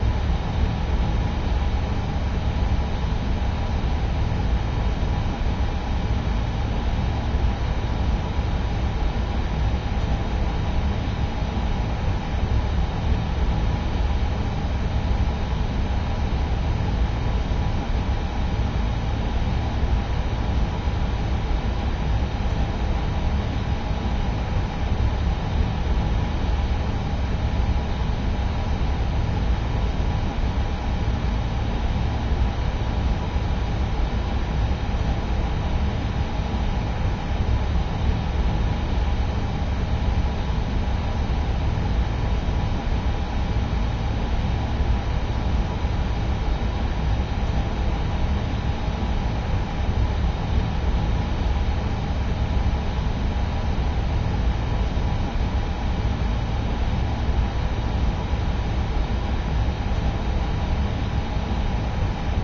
Speed 90%